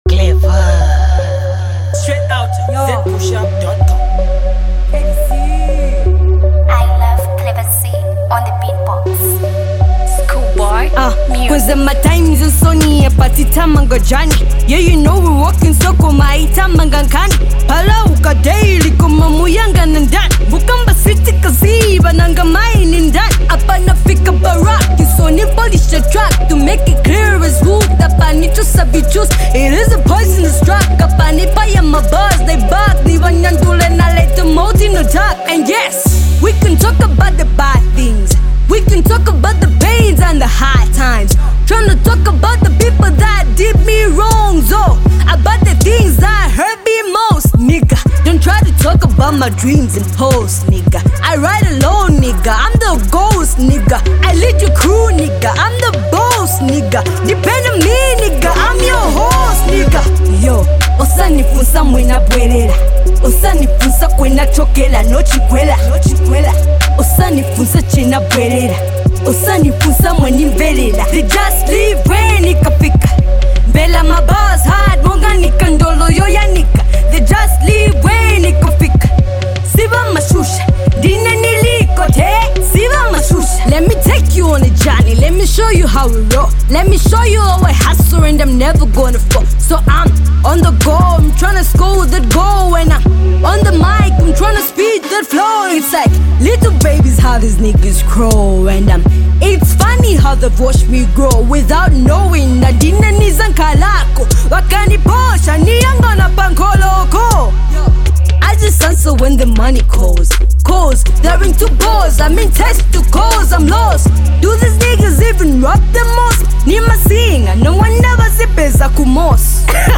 Zambian Female Rapper
HipHop
her hardest bars